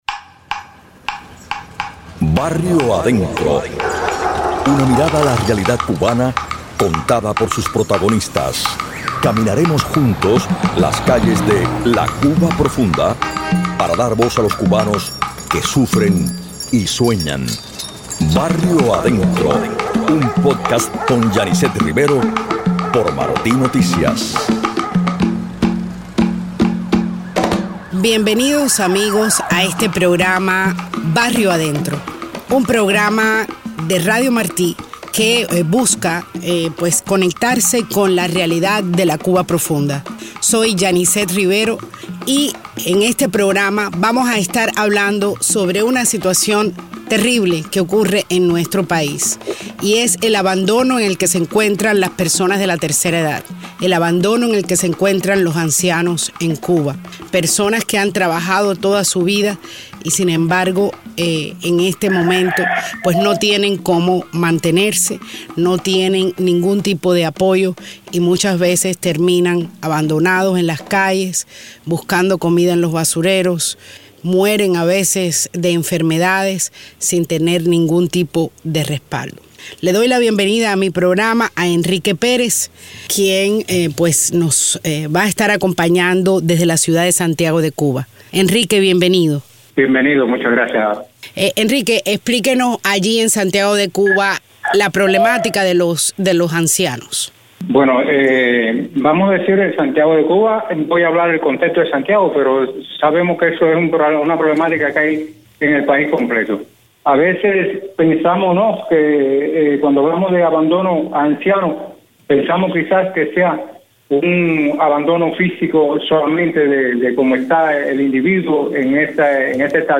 Escucharan testimonios desde Santiago y Cienfuegos, además de fragmentos grabados dentro de Cuba por periodistas de la agencia de prensa independiente “Palenque Visión”.